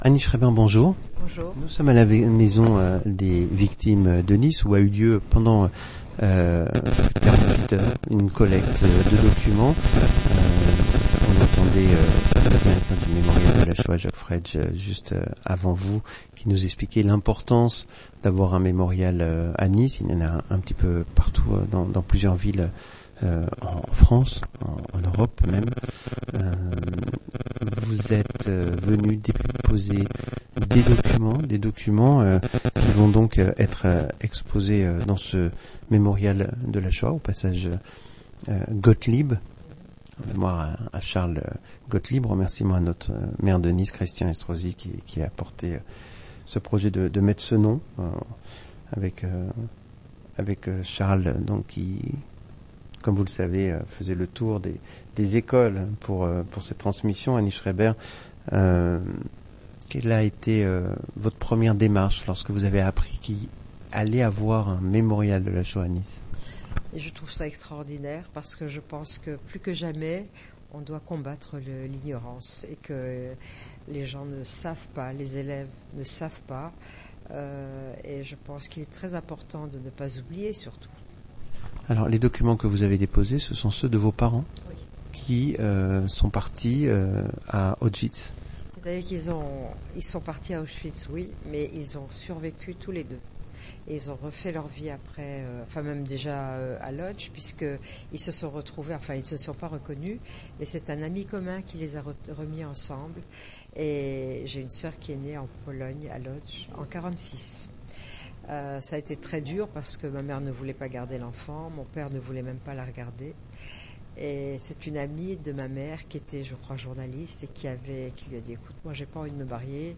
Ecoutez le monde qui bouge Interviews